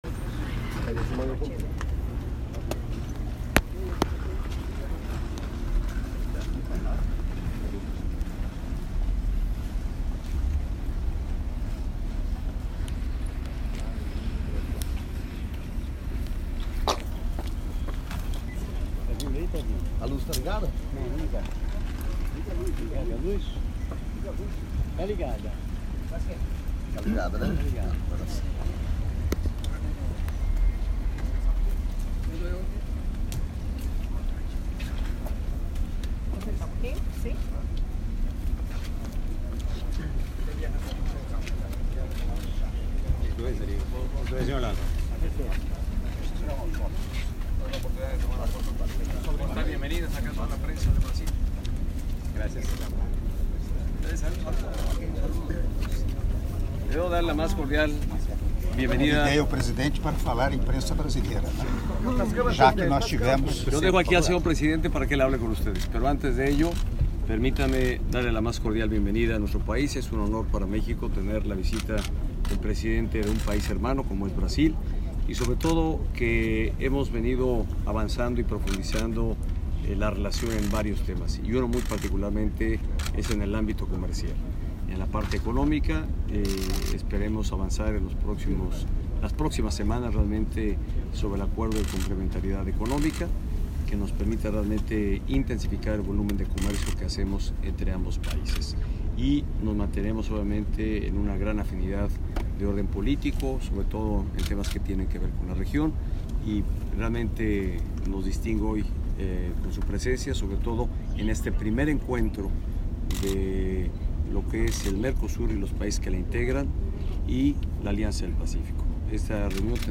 Áudio da Entrevista coletiva concedida pelo Presidente da República, Michel Temer, após reunião bilateral com o Presidente dos Estados Unidos Mexicanos, Enrique Peña Nieto - Puerto Vallarta/México (05min4s)